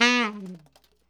TNR SHFL B3.wav